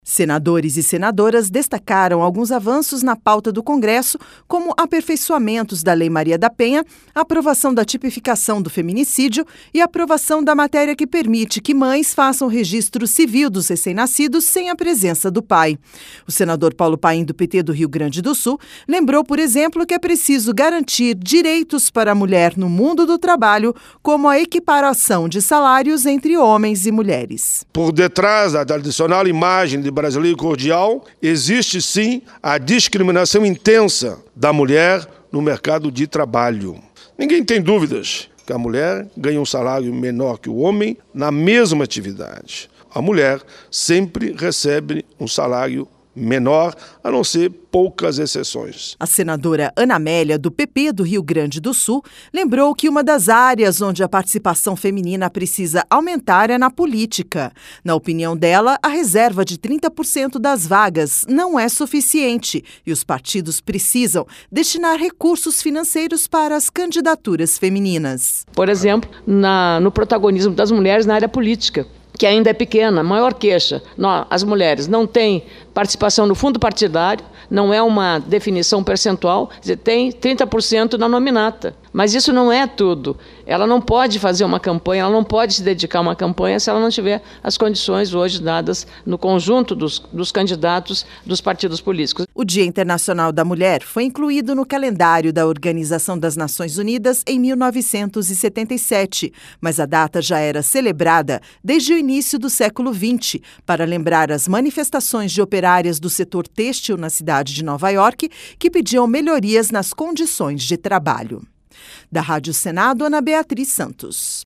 O DIA INTERNACIONAL DA MULHER, COMEMORADO DO DIA 8 DE MARÇO FOI LEMBRADO NO PLENÁRIO DO SENADO NESTA SEXTA-FEIRA. LOC: OS SENADORES LEMBRARAM QUE É PRECISO AMPLIAR OS DIREITOS DAS MULHERES, QUE FORMAM METADE DA POPULAÇÃO BRASILEIRA.